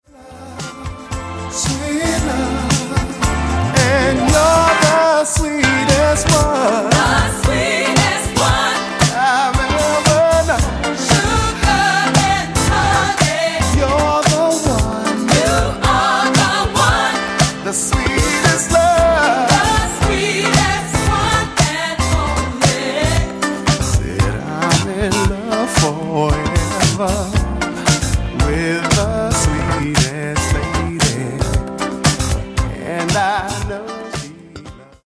Genere:   Funky | Soul